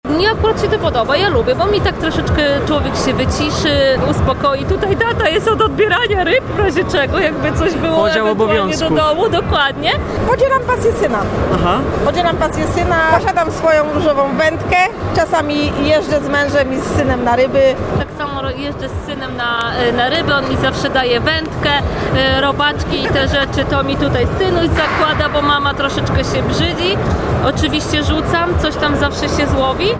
Wśród odwiedzających dominują panowie, ale w związku z dniem kobiet, nasz reporter postanowił sprawdzić, czy panie równie przyszły na rabomanię.